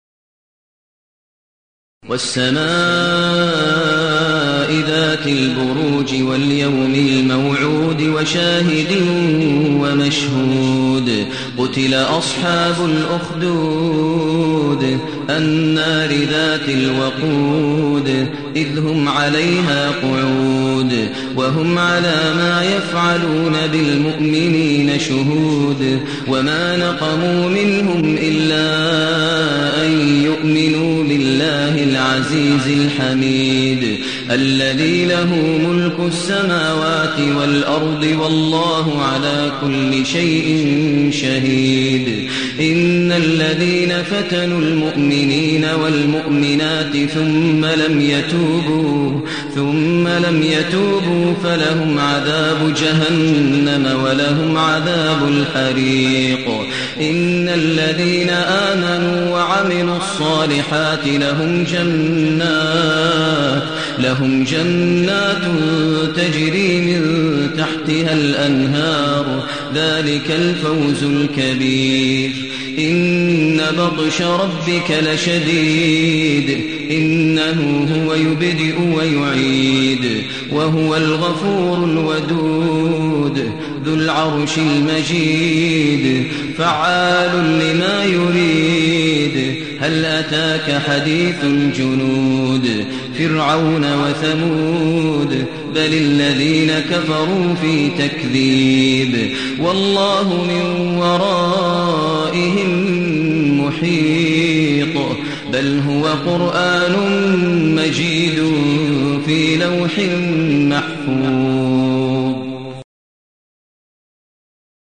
المكان: المسجد النبوي الشيخ: فضيلة الشيخ ماهر المعيقلي فضيلة الشيخ ماهر المعيقلي البروج